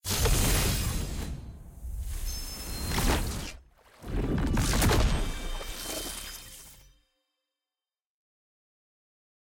sfx-exalted-rolling-ceremony-single-gold-anim.ogg